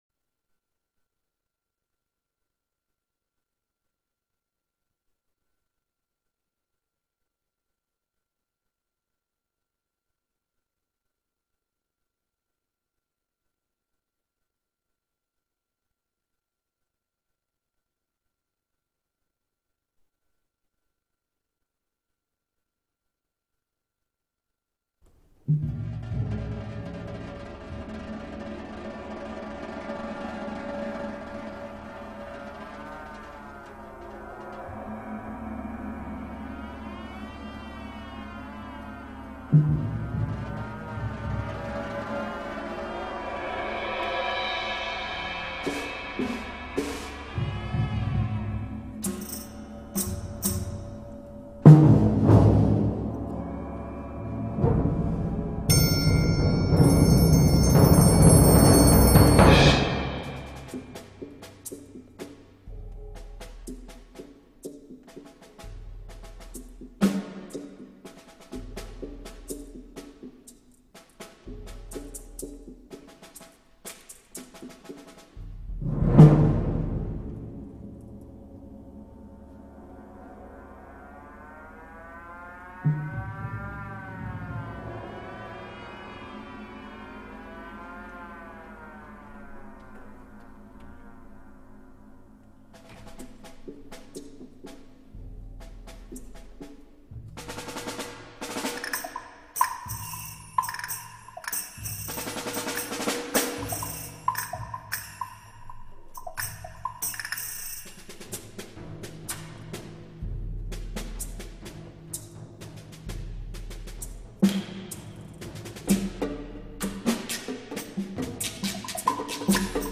percusion video varese.mp4